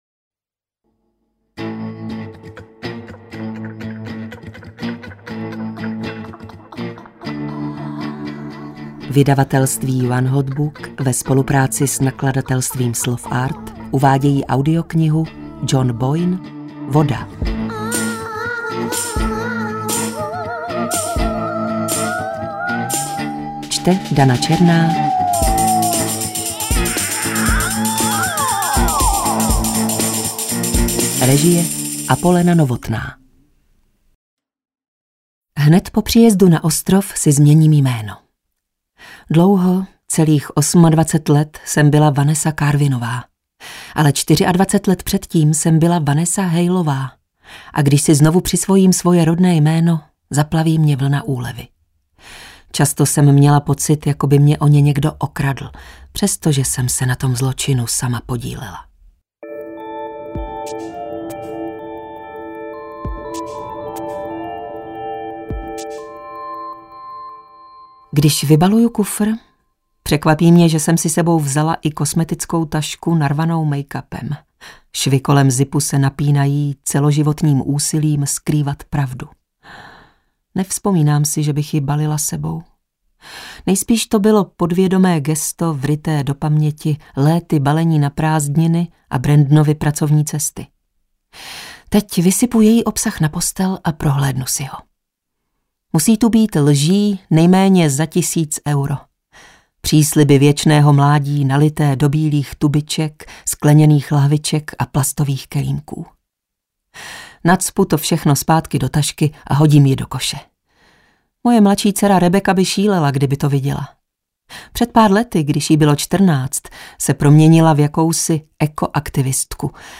Voda audiokniha
Ukázka z knihy